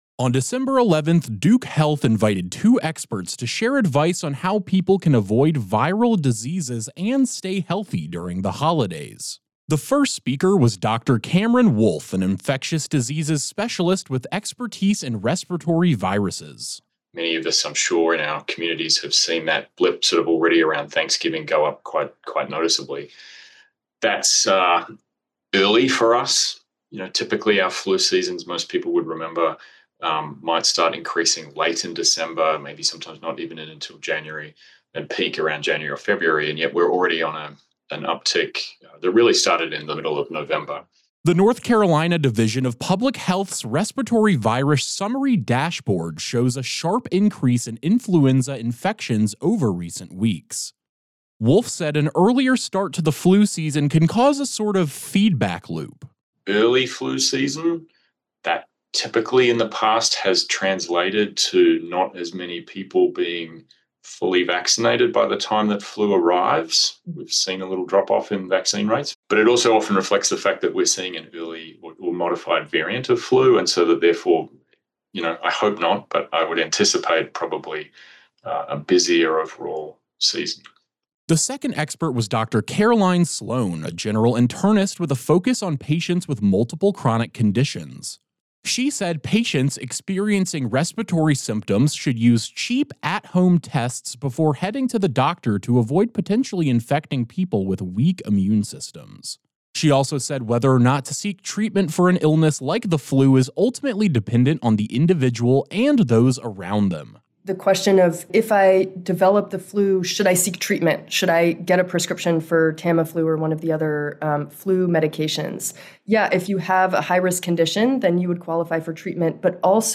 On Dec. 11, Duke Health invited two experts to share advice on how people can avoid viral illnesses and stay healthy during the holidays at a press conference.